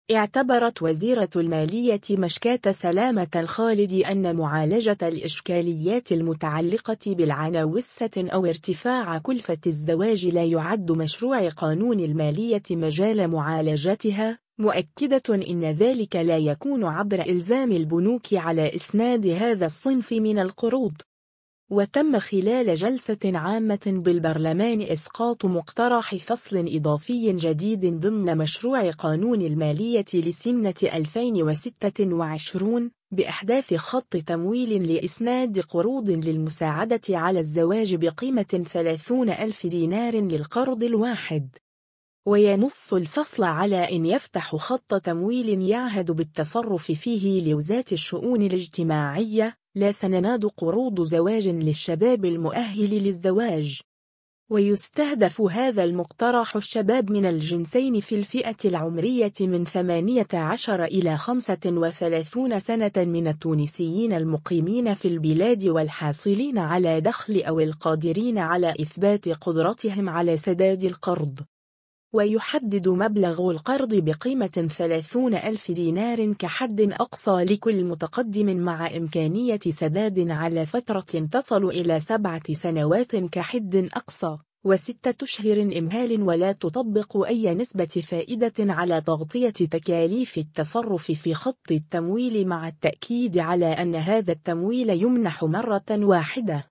وتم خلال جلسة عامة بالبرلمان إسقاط مقترح فصل إضافي جديد ضمن مشروع قانون المالية لسنة 2026, بإحداث خط تمويل لإسناد قروض للمساعدة على الزواج بقيمة 30 الف دينار للقرض الواحد.